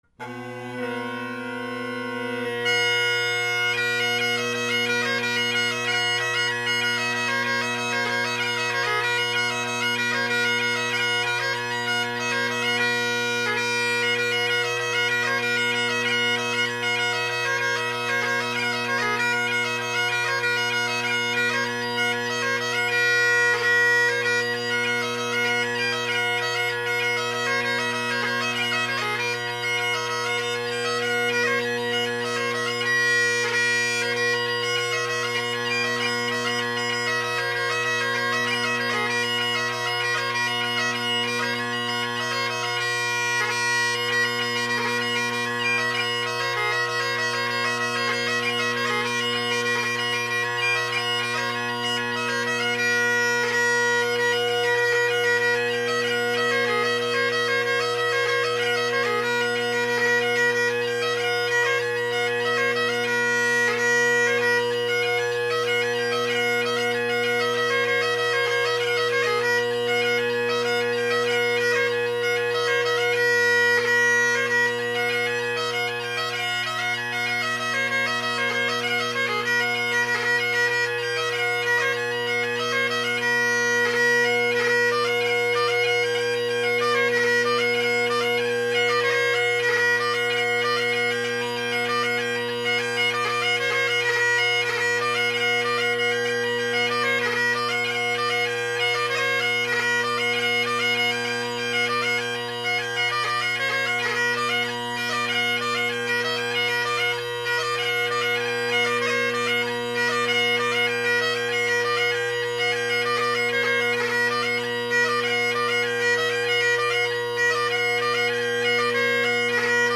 Drone Sounds of the GHB, Great Highland Bagpipe Solo
I guess I should have recorded these with the mic in front of me instead of behind for once, these drones are loud with these reeds!